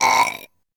belch.ogg